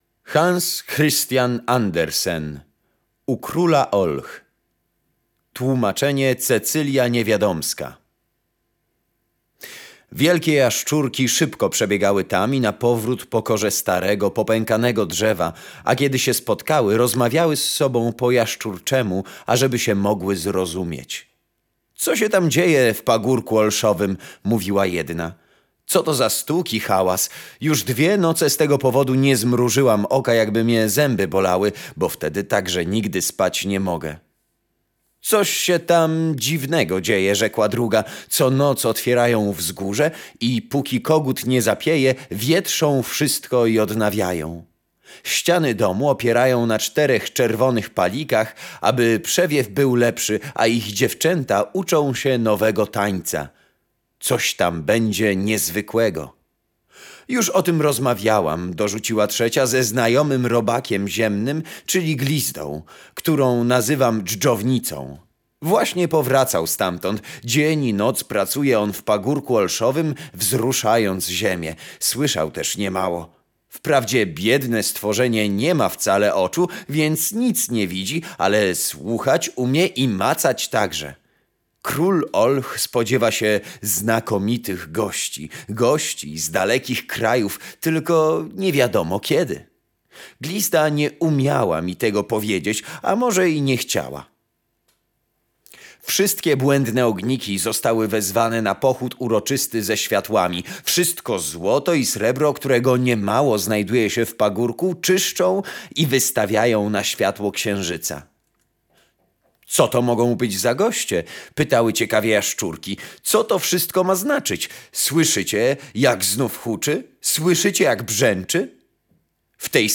Bajki, legendy, mity, opowieści - lubię czytać i chętnie poczytam zostawiając przy okazji coś dla innych.